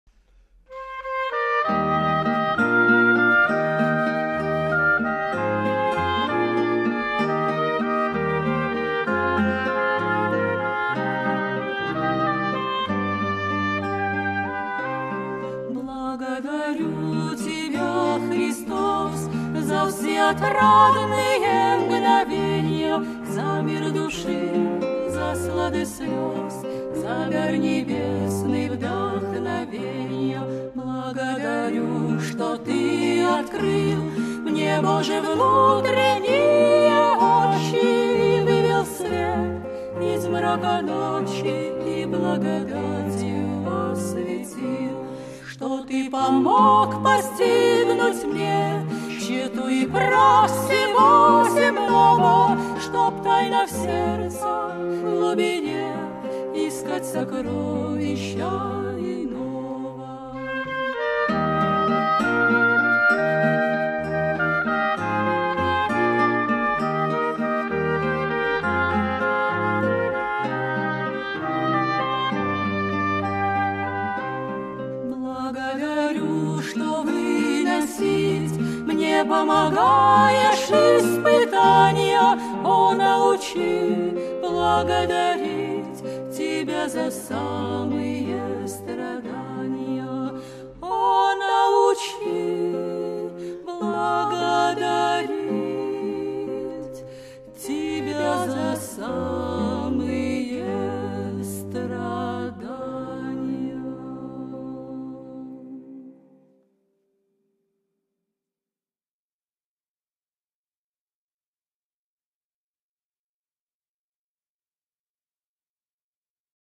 контр-альто
лирическое сопрано